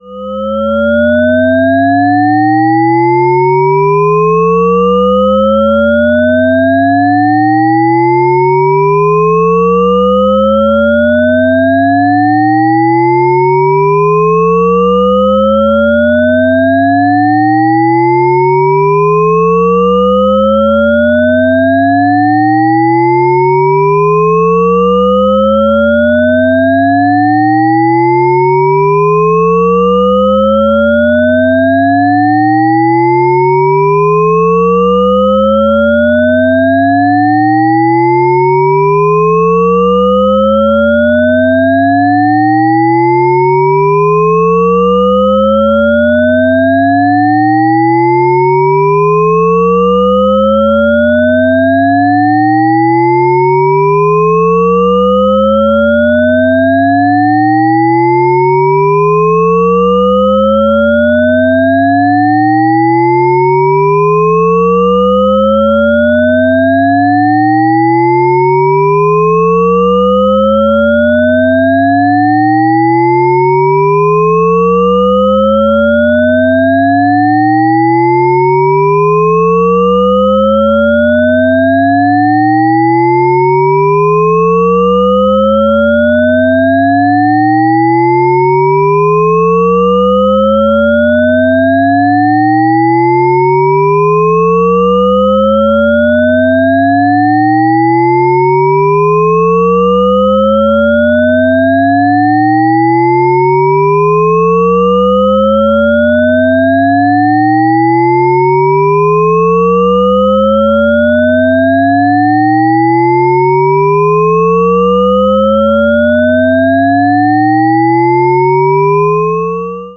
increasing Shepard-Risset glissando and the ever
shepard_risset_glissando_upward.ogg